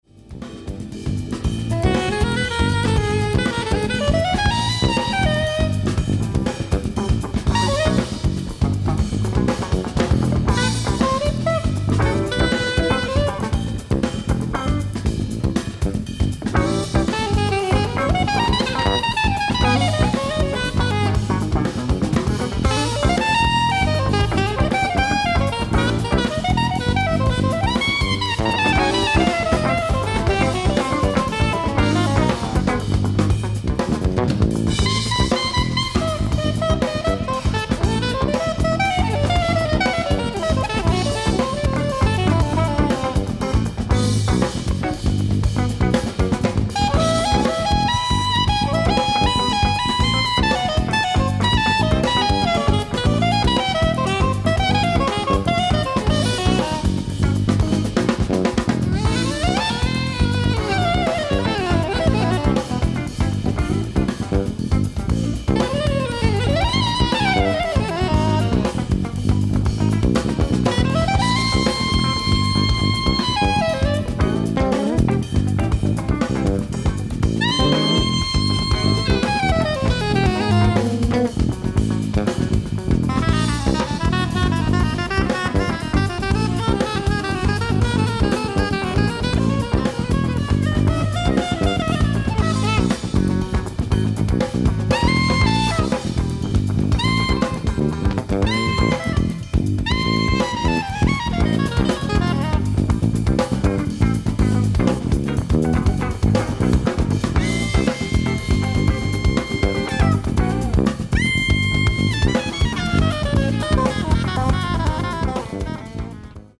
Disc 1 & 2：ライブ・アット・パリ、フランス 04/09/2000
※試聴用に実際より音質を落としています。